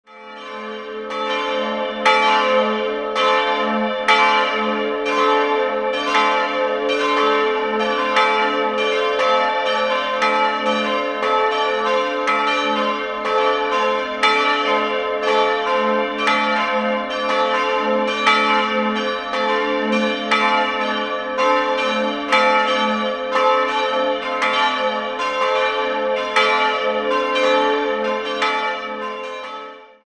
Jahrhundert im Barockstil umgestaltet wurde. 3-stimmiges TeDeum-Geläute: a'-c''-d'' Dieses Schilling-Geläute wurde 1958 gegossen. Die Glocken wiegen 478, 282 und 187 kg.